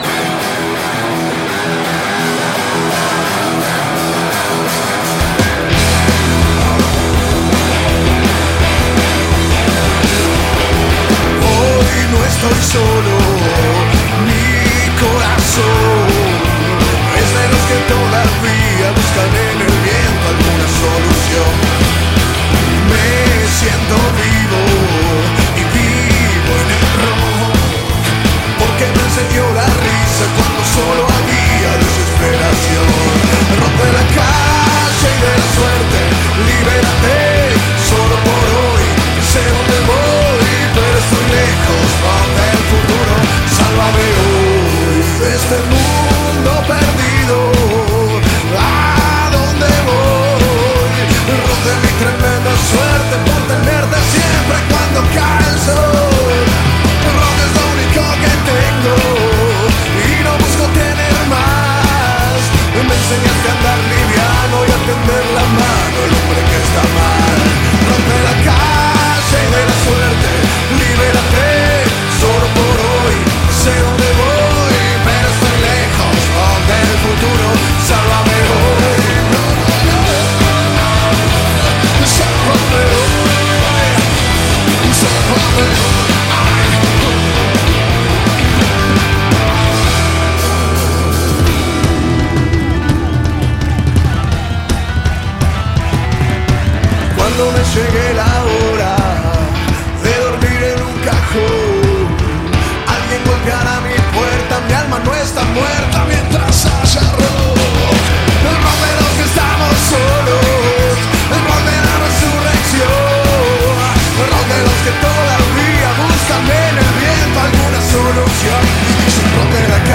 02 - rock (en vivo).mp3